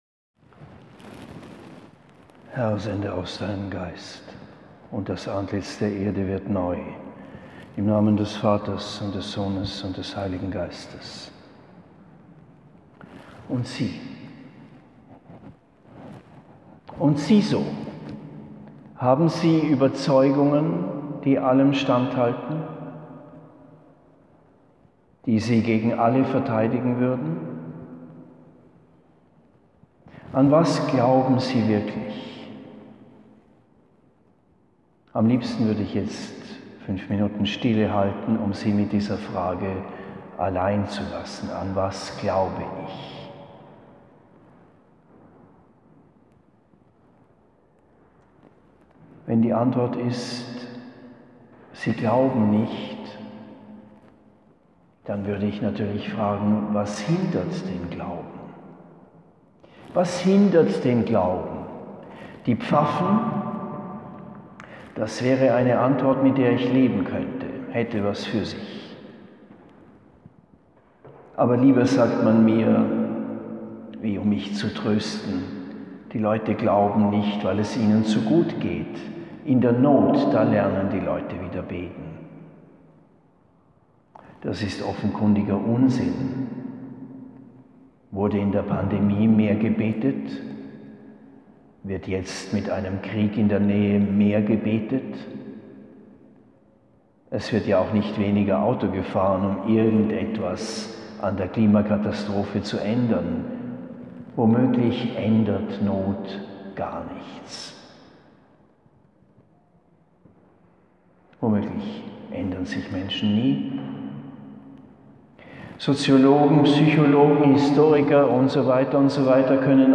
Die Predigt in Mailberg!